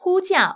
ivr-call.wav